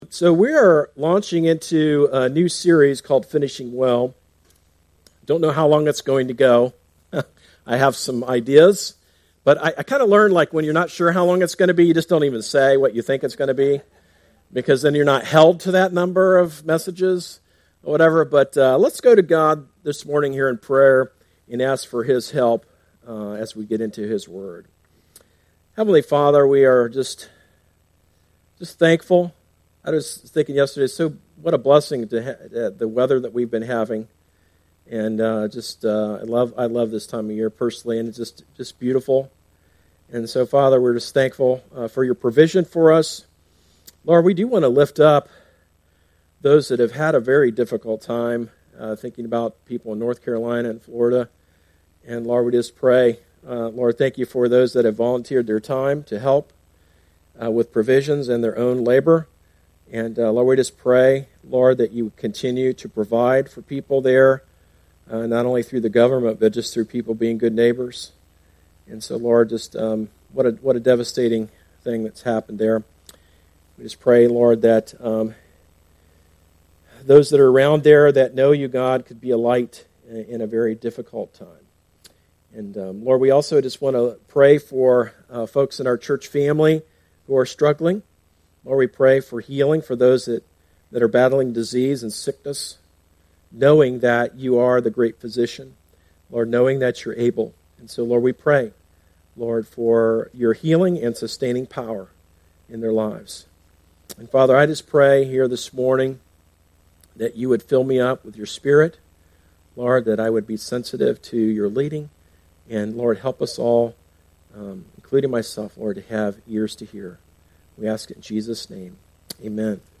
A message from the series "Book Of Esther."